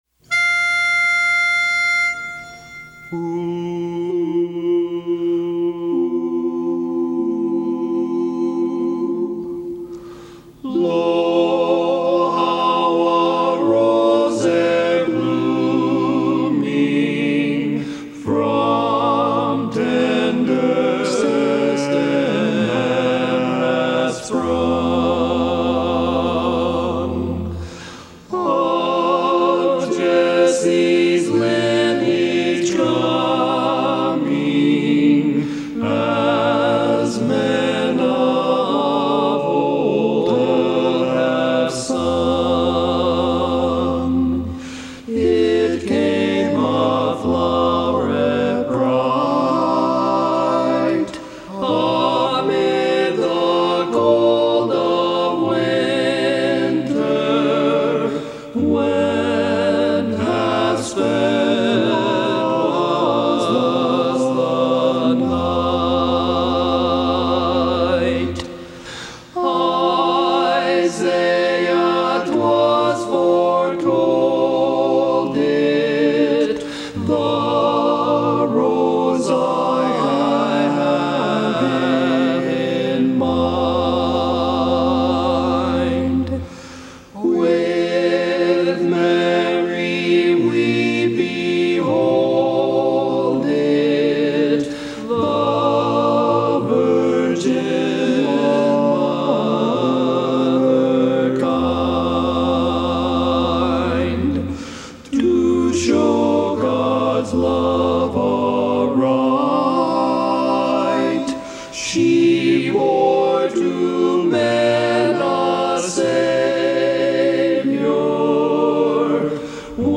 Barbershop
Tenor